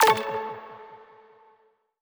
button-back-select.wav